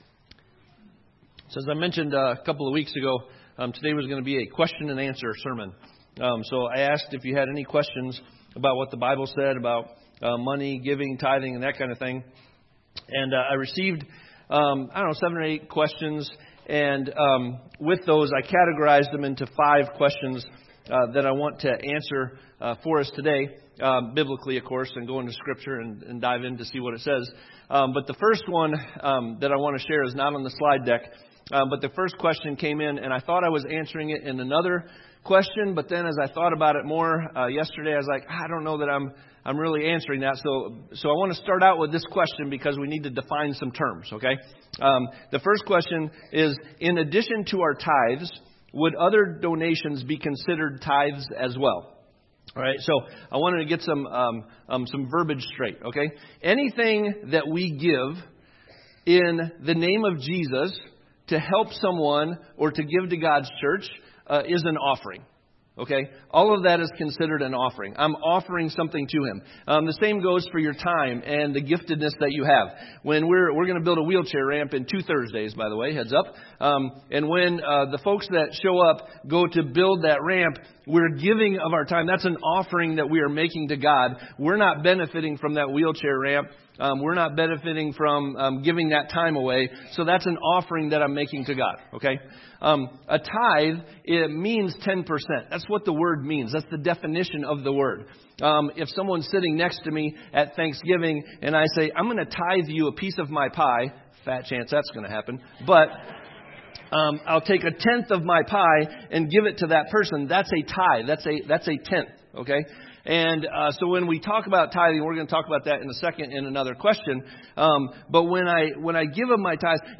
A question and answer sermon